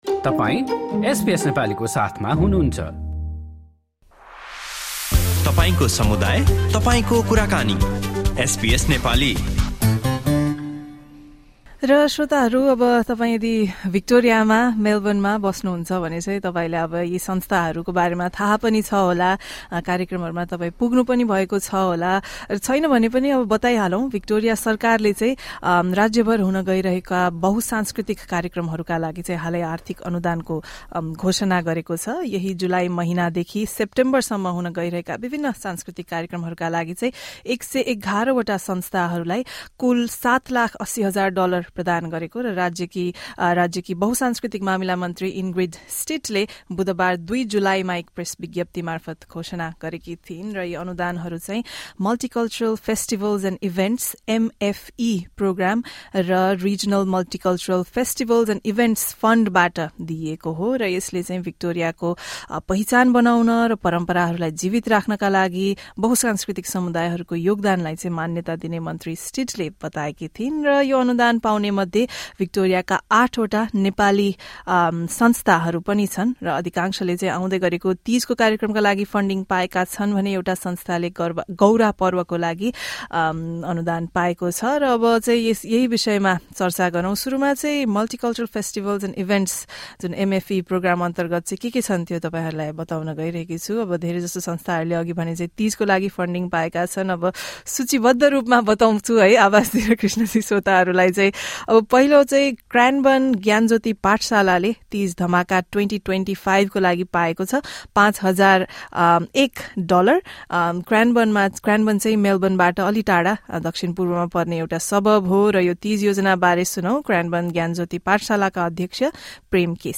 Nepali community organisations in Victoria have received funding from the state government to help them organise Teej and Gaura events in the coming months. We spoke to the representatives of the recipient organisations.